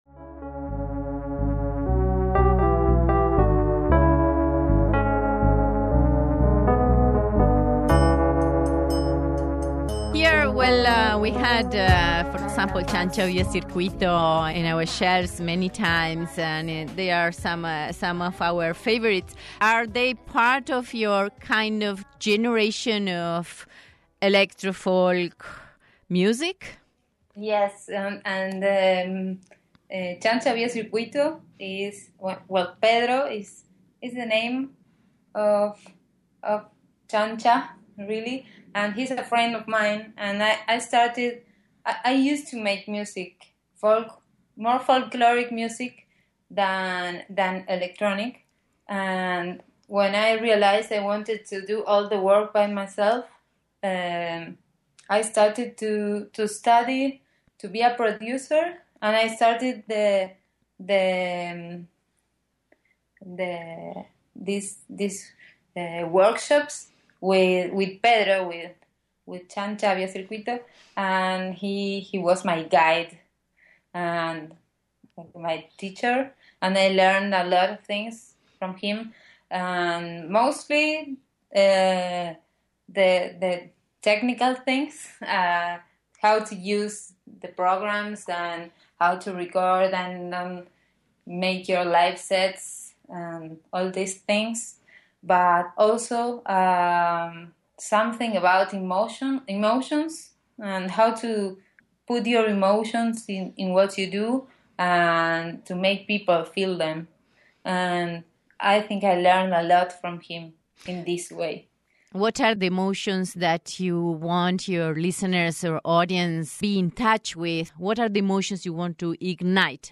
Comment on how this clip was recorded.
Originally aired on KUNM (March 4, 2017)